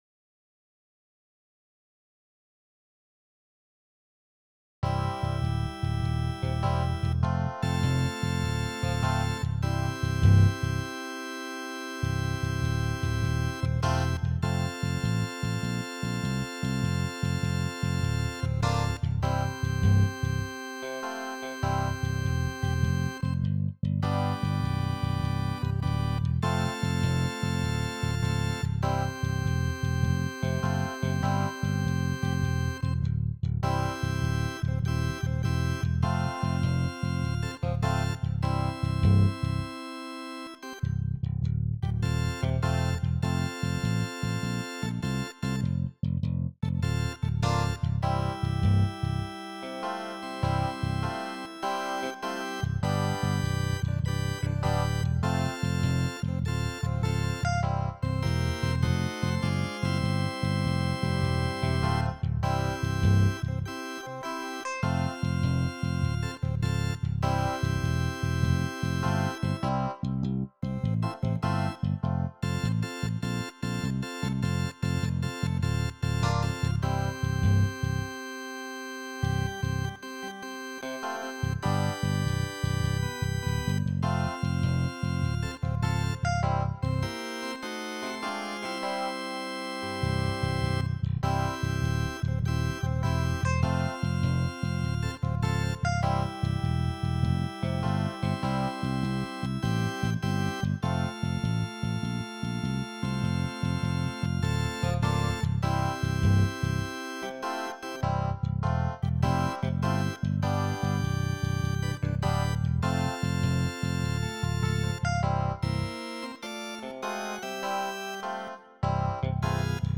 Ich habe mal 3 Klangbeispiele erzeugt.
modo-bass-2-blues.mp3